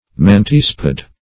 mantispid \man*tis"pid\, n. (Zool.)